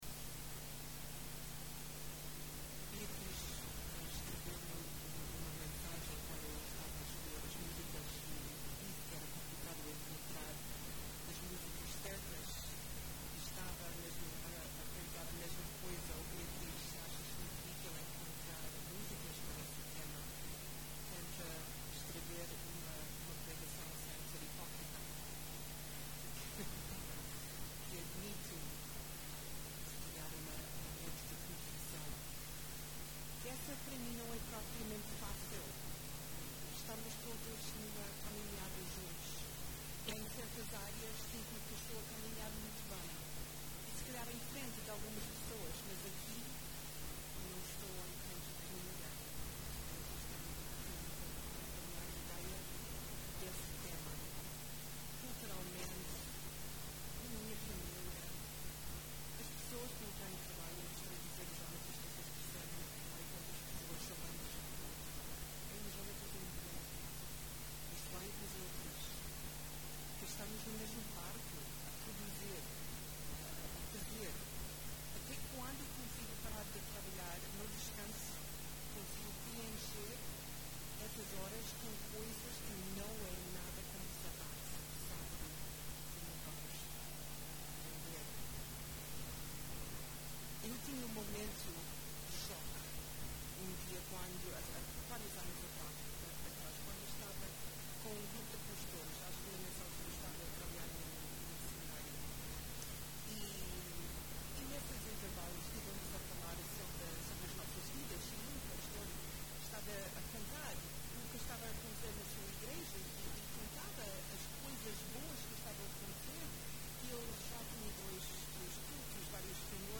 mensagem bíblica O que é que estamos a tentar alcançar, provar ou ganhar?